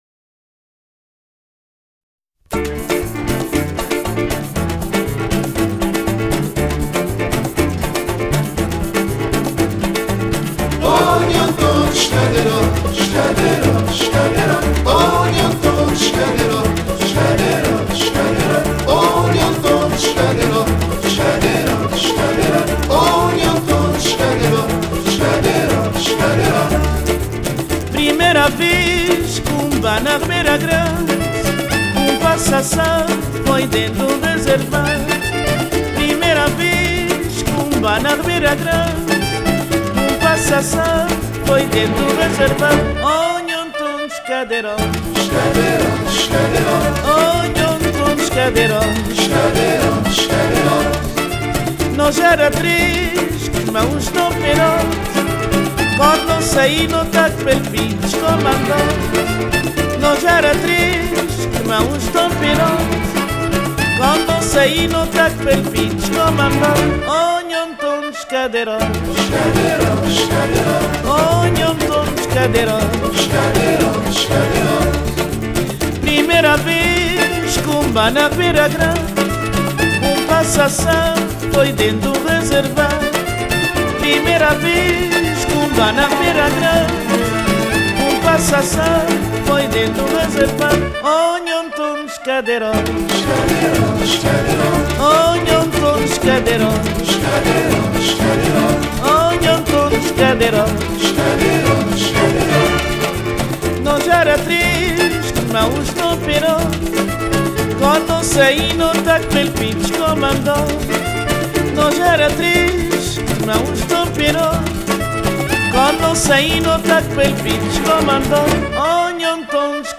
Под музыку, напоминающую смесь шансонных традиций и босановы